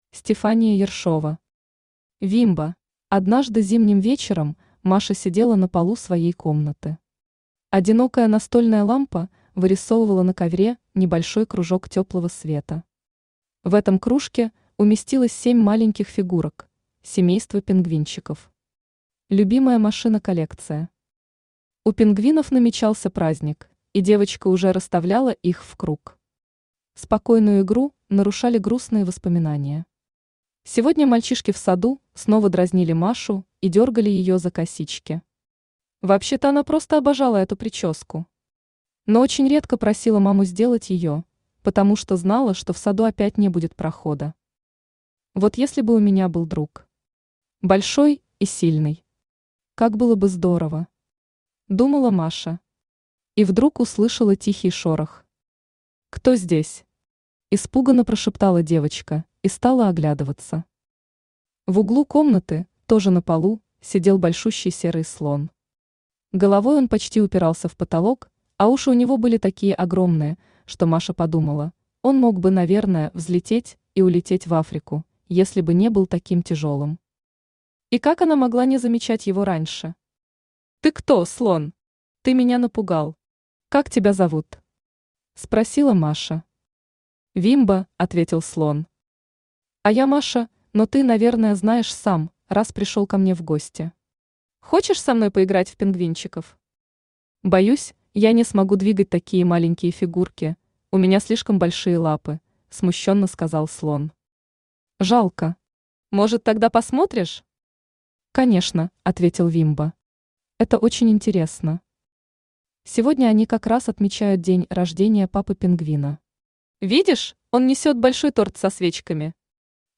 Аудиокнига Вимбо | Библиотека аудиокниг
Aудиокнига Вимбо Автор Стефания Ершова Читает аудиокнигу Авточтец ЛитРес.